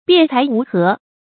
辯才無閡 注音： ㄅㄧㄢˋ ㄘㄞˊ ㄨˊ ㄏㄜˊ 讀音讀法： 意思解釋： 佛教語。